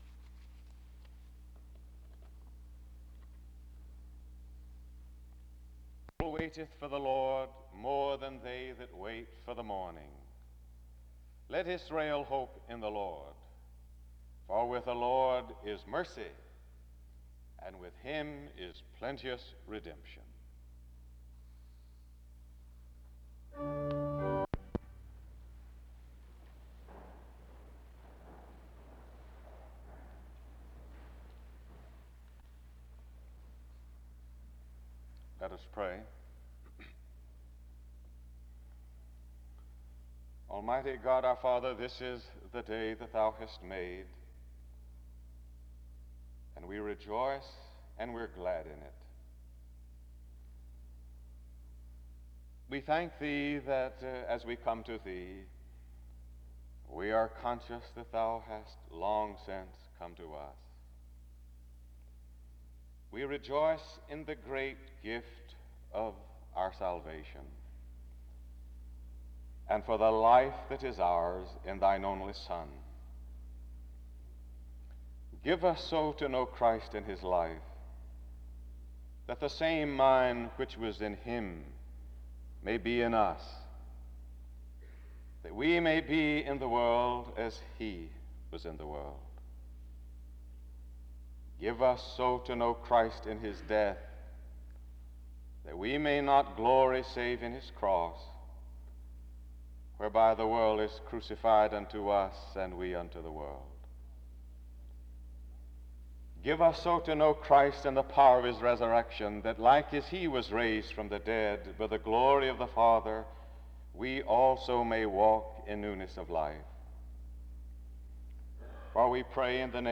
Download .mp3 Description The service begins with the reading of Psalm 130:6-7 (00:00-00:34), prayer (00:35-02:40), and a brief responsive reading (02:41-03:06).